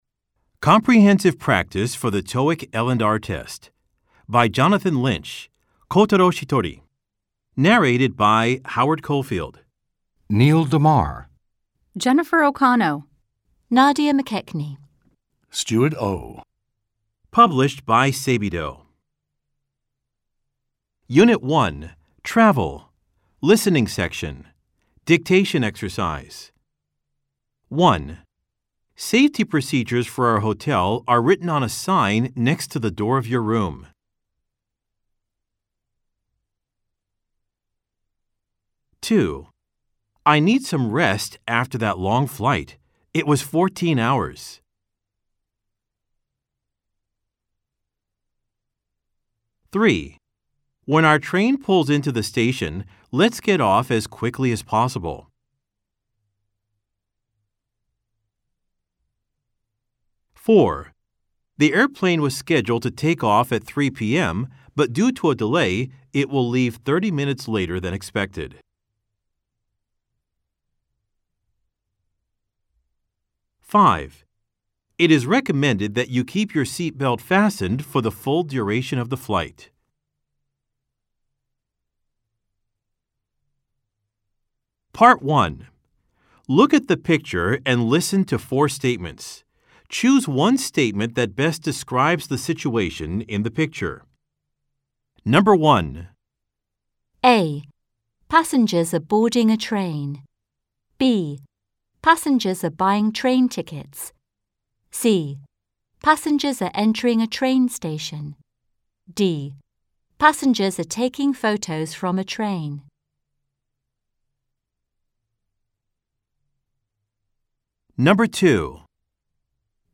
吹き込み Amer E ／ Brit E ／ 他